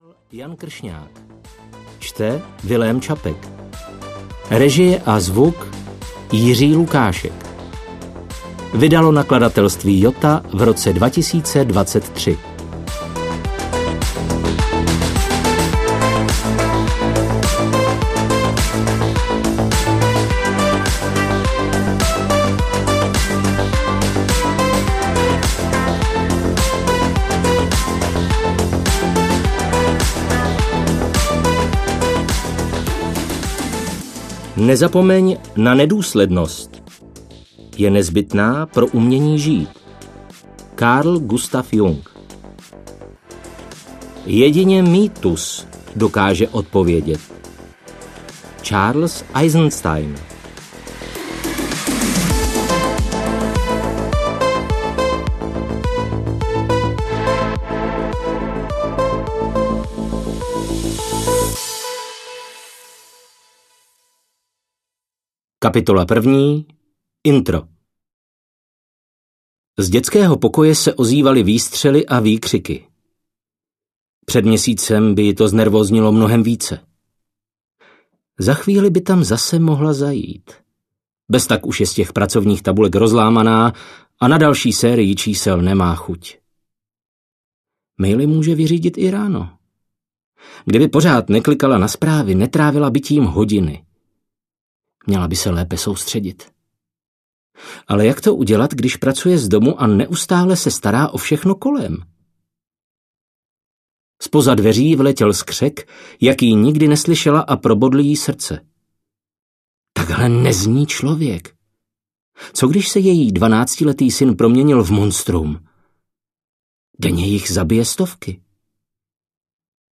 Digiděti audiokniha
Ukázka z knihy